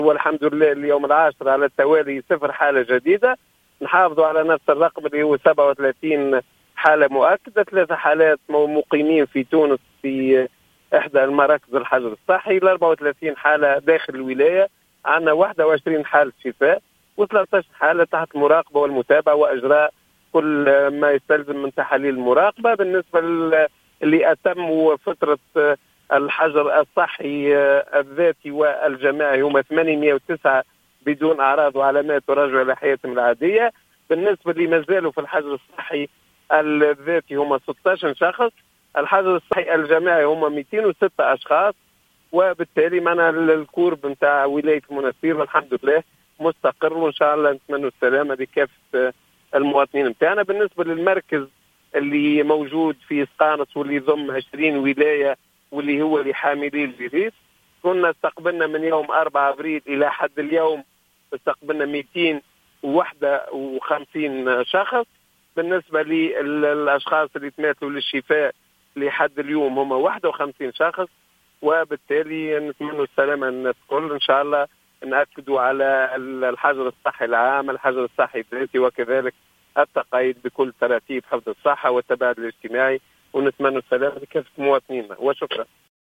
أكد المدير الجهوي للصحة بالمنستير، المنصف الهواني في تصريح اليوم لـ"الجوهرة أف أم" عدم تسجيل أي إصابات جديدة بفيروس "كورونا" في الجهة، وذلك لليوم العاشر على التوالي ليستقر بذلك العدد الجملي للإصابات عند حدود 37 إصابة.